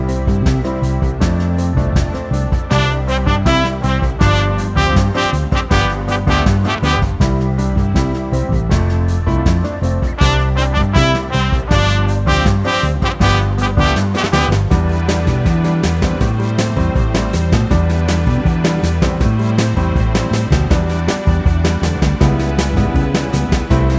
no Backing Vocals Soul / Motown 3:00 Buy £1.50